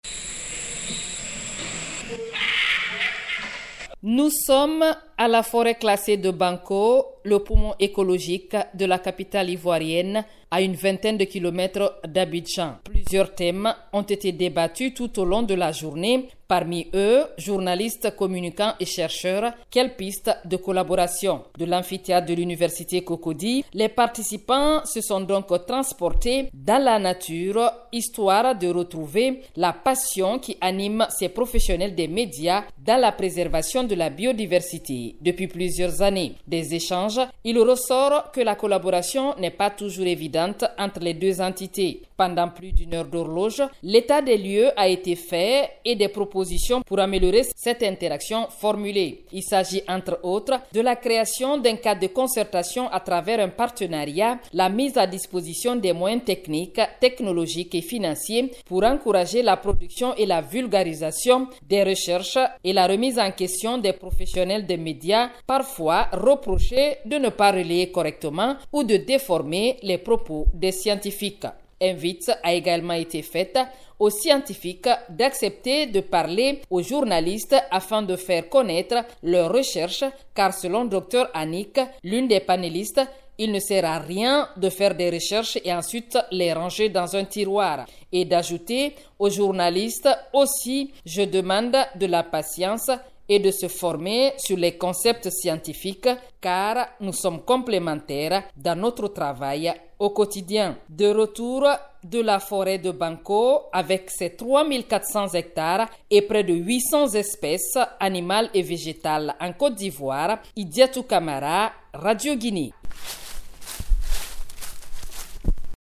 Sur le continent à Abidjan, les journalistes présents à la conférence mondiale scientifique, étaient ce mercredi à la forêt classée de banco, située à une trentaine de kilomètres de la capitale Ivoirienne.